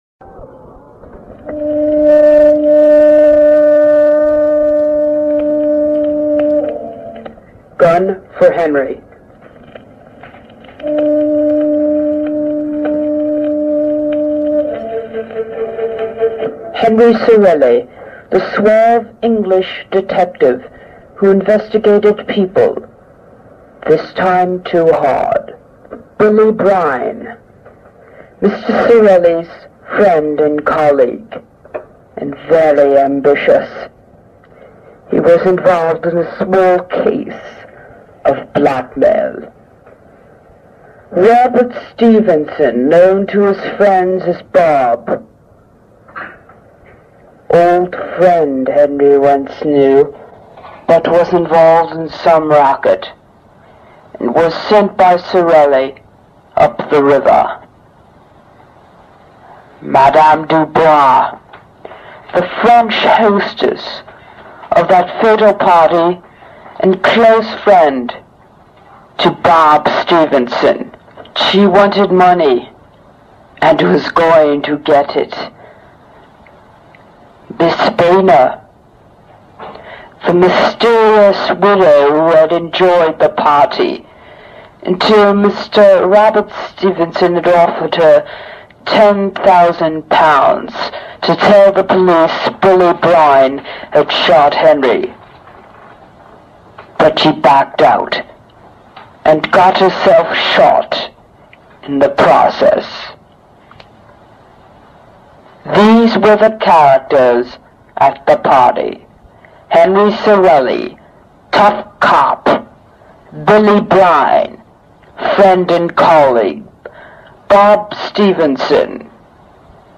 Everything about the 24-episode tape-recorder series was puzzling.